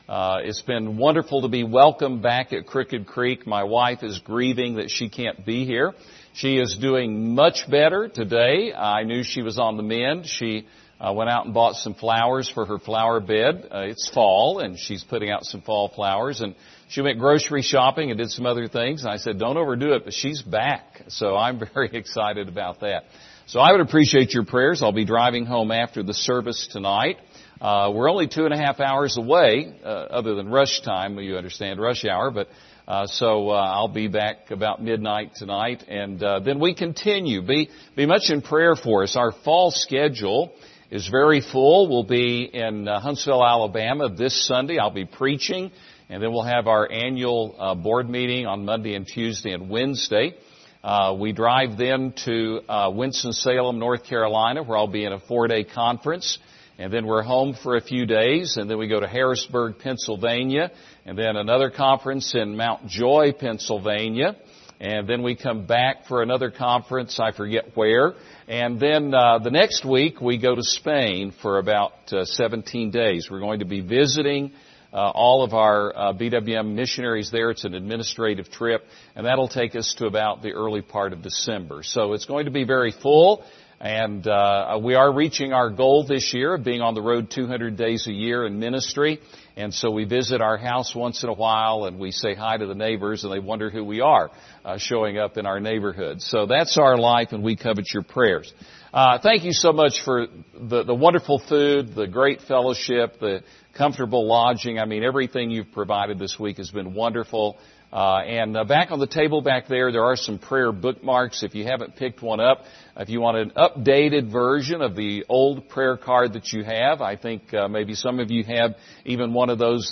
Series: 2023 Missions Conference
Service Type: Special Service